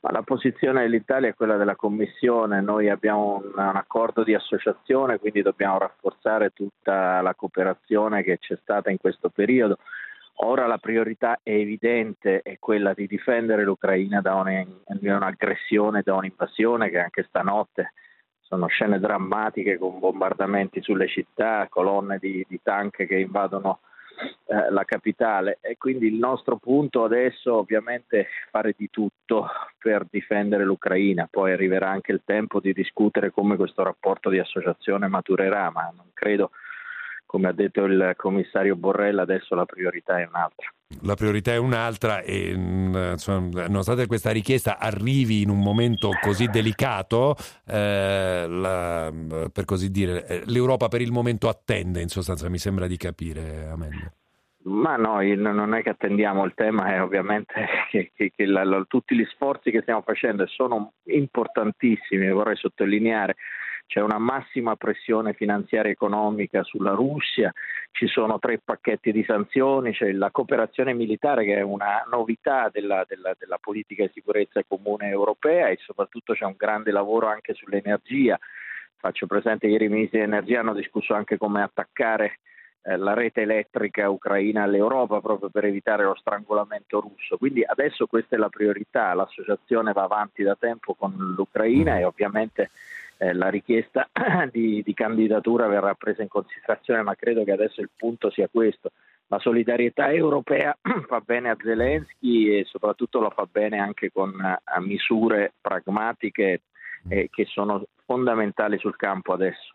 Guerra Ucraina – Amendola, sottosegretario agli Affari Europei, a 24 Mattino su Radio 24: Ora priorità è aiutare Ucraina, poi si discuterà di adesione a Ue